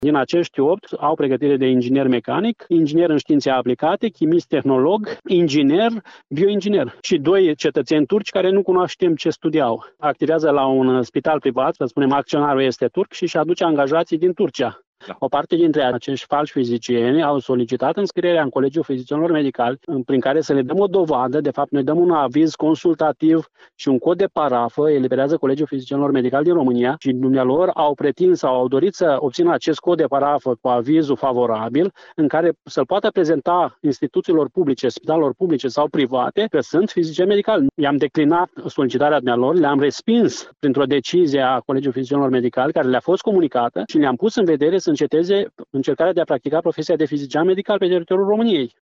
Într-o declaraţie acordată Radio Iaşi